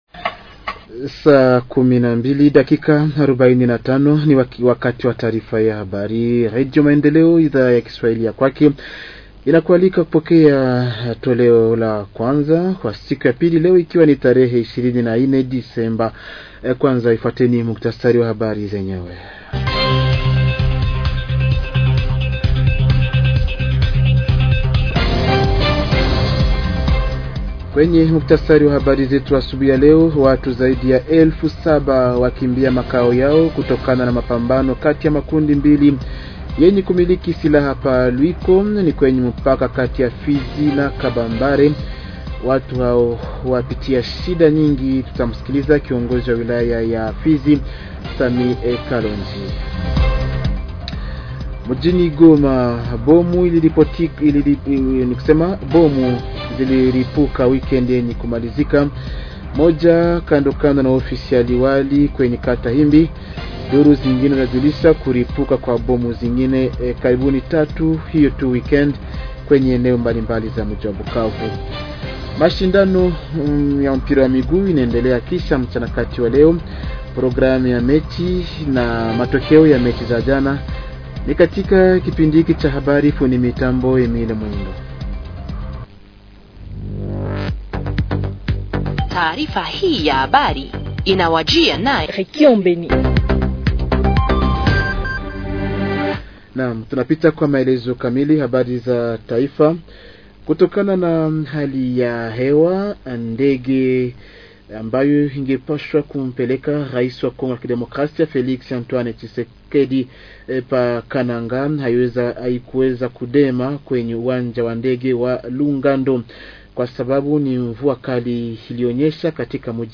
Journal Swahili du 24 décembre 2024 – Radio Maendeleo